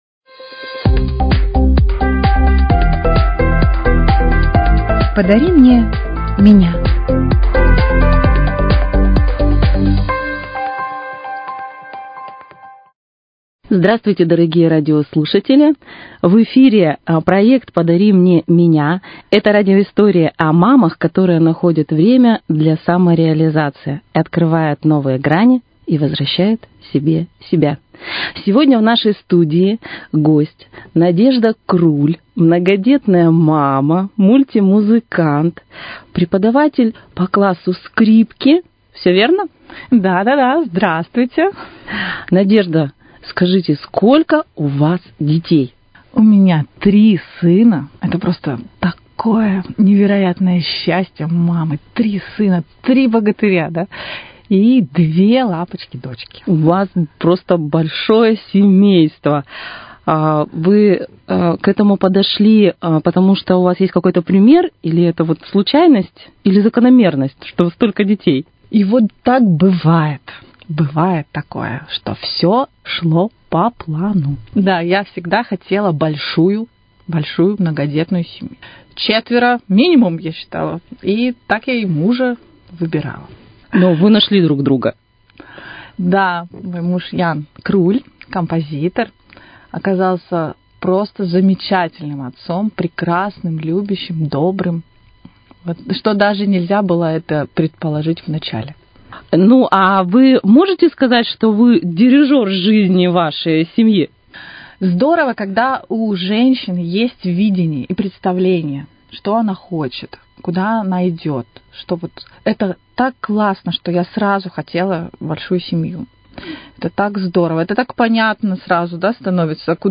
Историю о том, как совмещать такую бурную творческую жизнь с материнством, как не потерять себя в суматохе дел вы услышите в этом интервью.